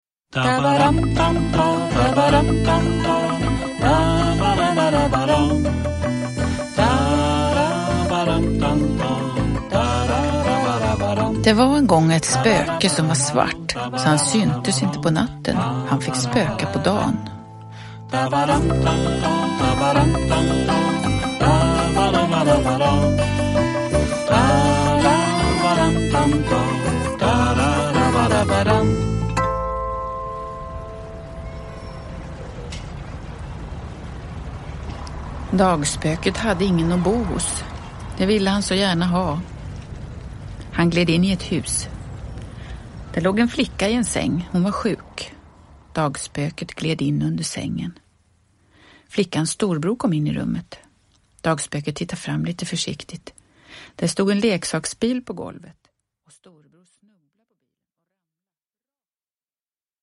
Dagspöket på sjukhus – Ljudbok – Laddas ner
Uppläsare: Jujja Wieslander